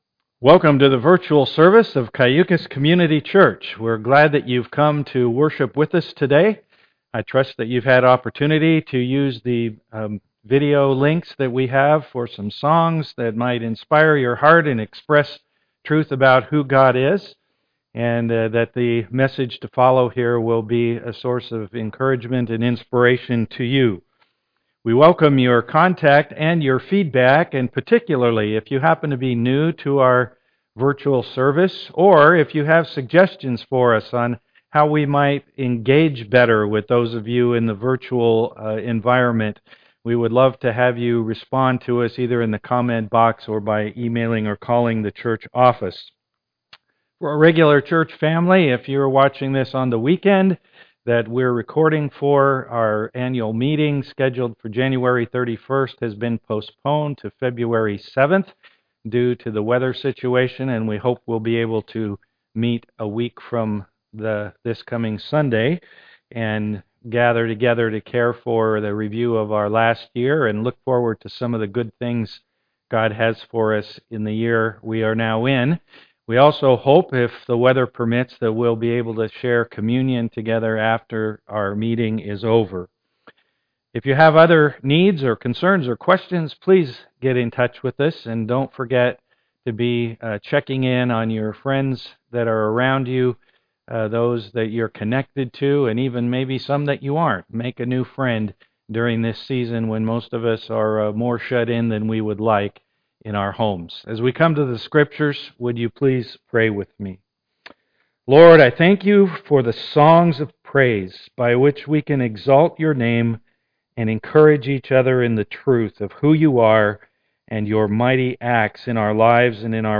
Series: Walking the (COVID-19) Wilderness With Moses Passage: Exodus 20:8-11 Service Type: am worship